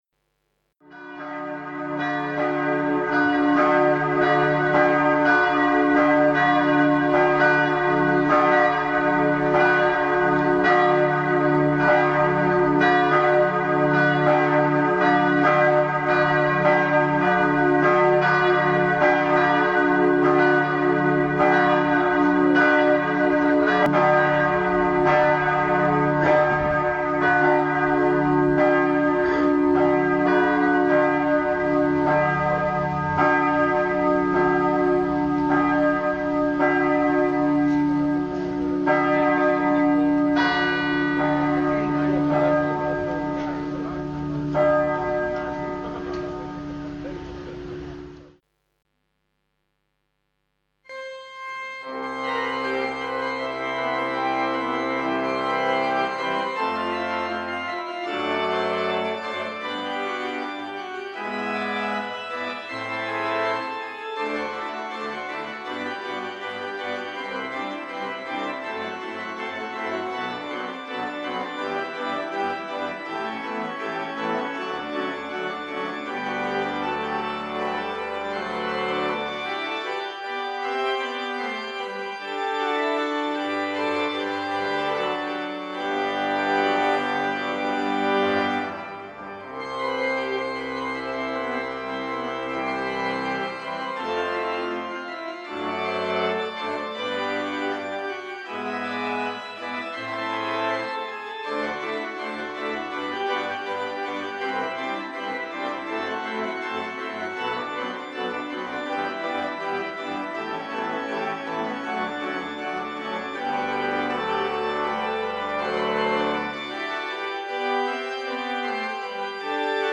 Hören Sie den Gottesdienst in unserer Kirche am 22. Juni 2025 (Predigttext: Johannes 5,39-47
Musikal. Begleitung: Posaunenchor Bunde
Gottesdienst-Aufnahme.mp3